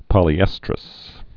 (pŏlē-ĕstrəs)